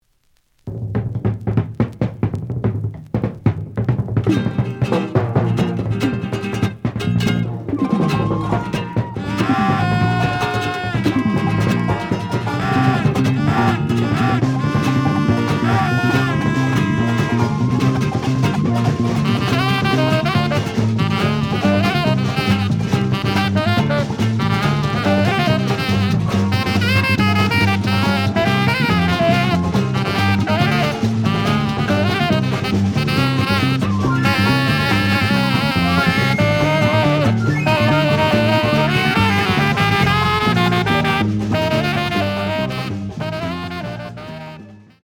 The audio sample is recorded from the actual item.
●Genre: Latin Jazz